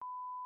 sine1khzs40dblong.mp3